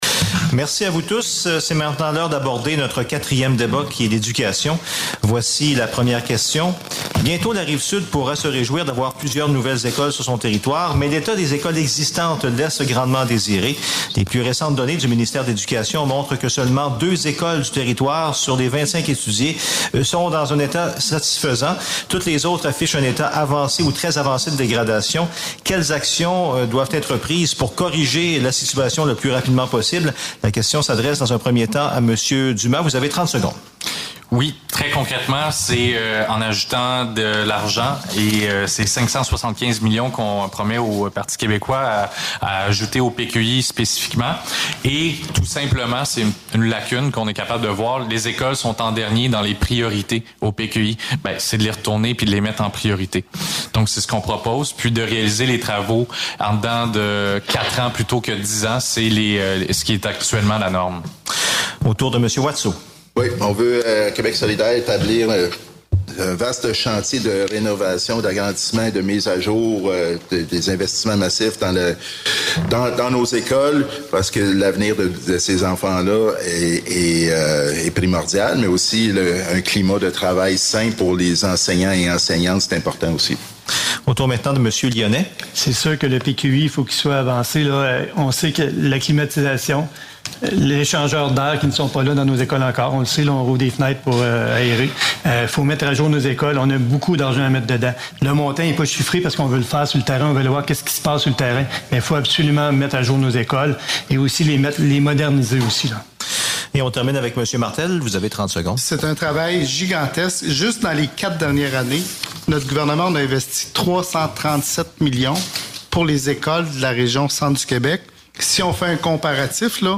C’est un débat généralement harmonieux auquel se sont livrés, le mardi 20 septembre, quatre des cinq candidats aux élections générales du 3 octobre dans Nicolet-Bécancour.
Débat des candidats dans Nicolet-Bécancour